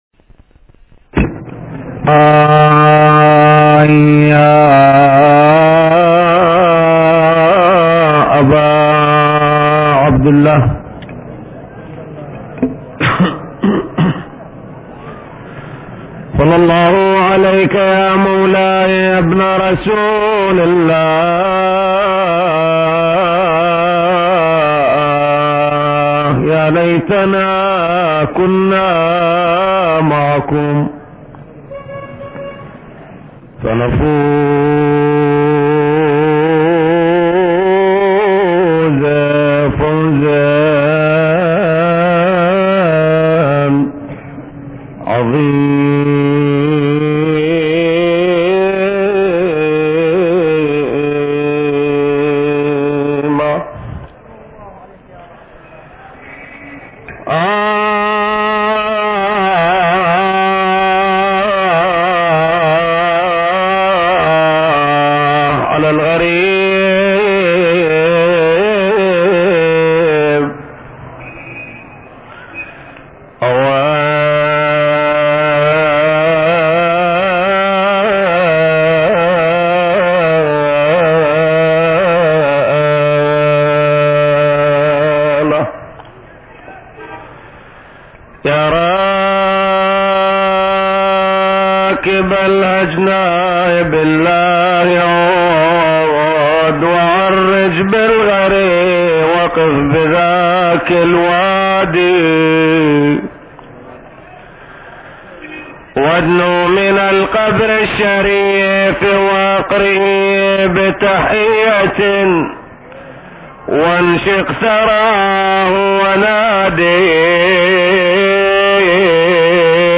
من مجالسه القديمه – في كربلاء – 5